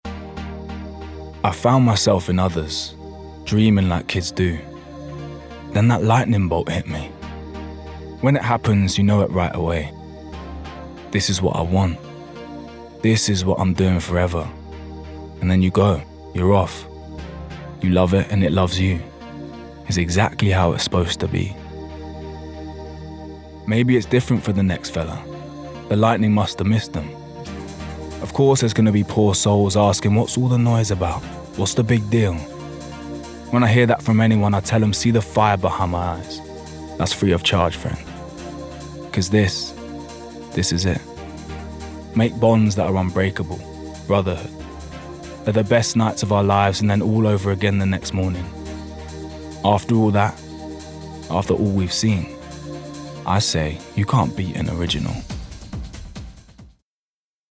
London
Male
Cool
Fresh
Smooth